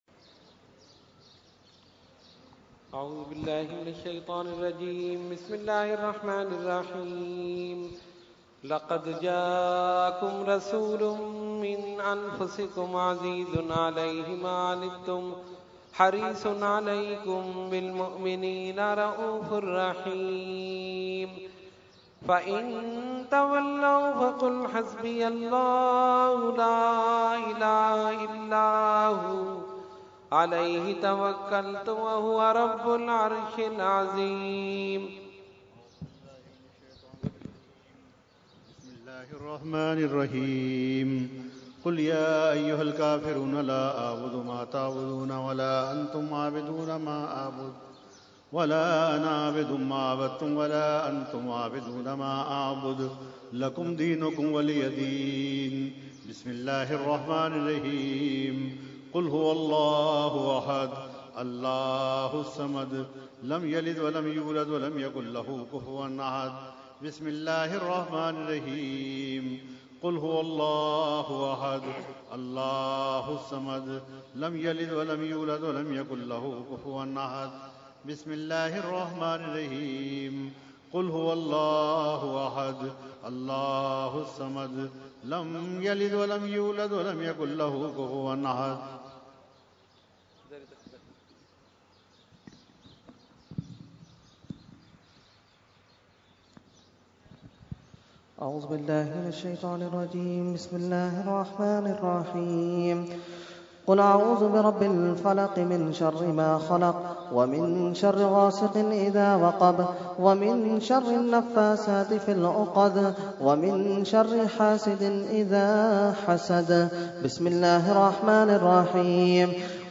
Fatiha – Urs Qutbe Rabbani 2016 – Dargah Alia Ashrafia Karachi Pakistan